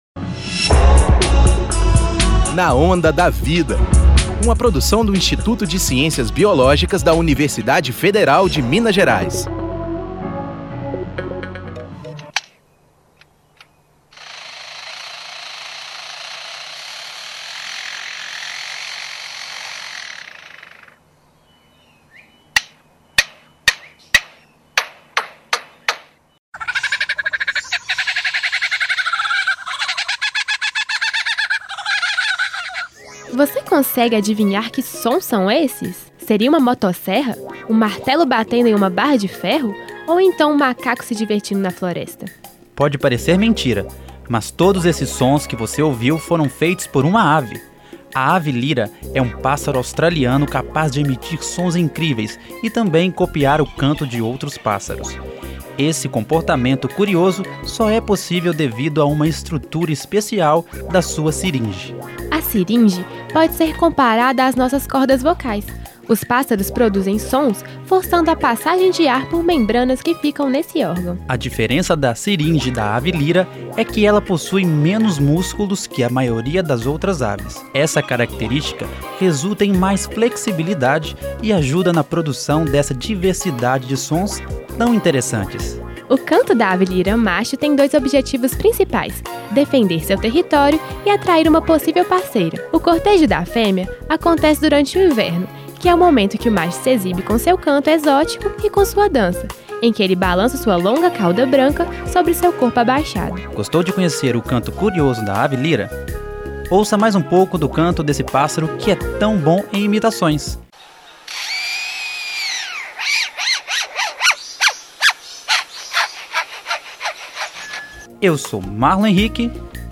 Você consegue adivinhar que sons são esses? Seria uma motosserra? Um martelo batendo em uma barra de ferro?